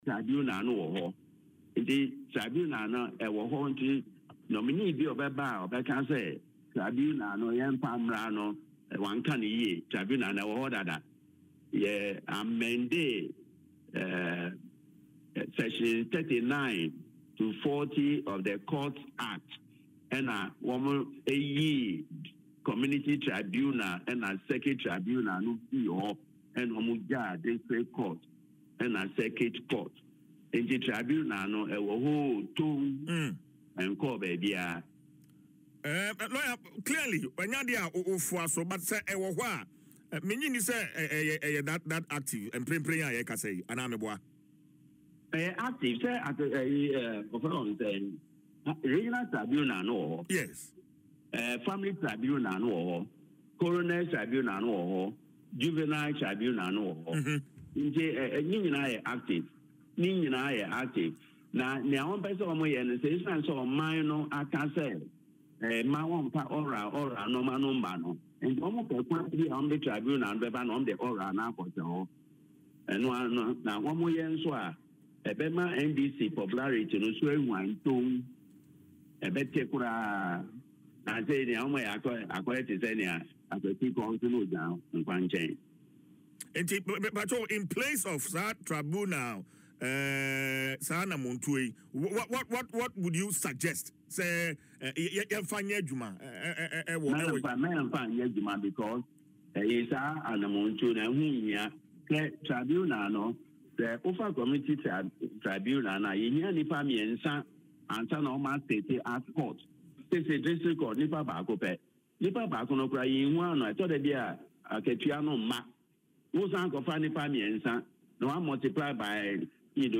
However, in an interview on Adom FM’s Dwaso Nsem